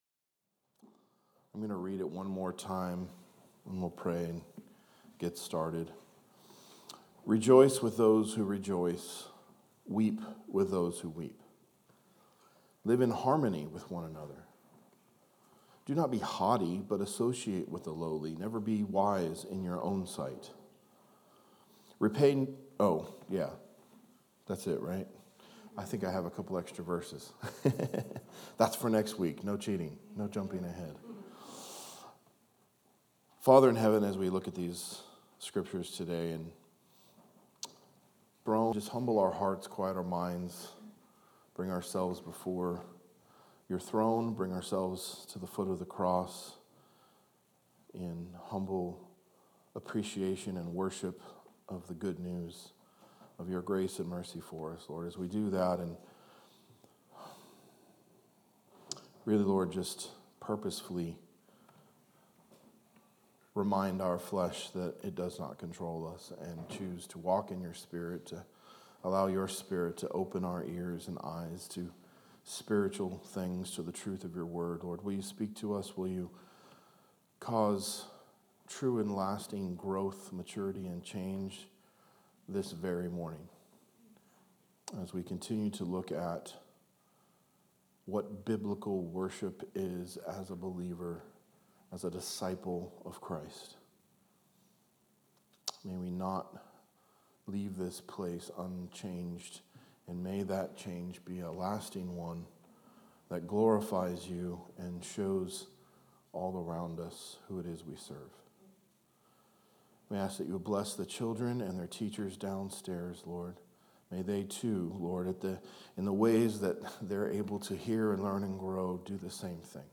A message from the series "Biblical Worship Series."